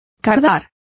Complete with pronunciation of the translation of backcombs.